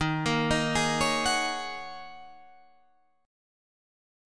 D#m11 Chord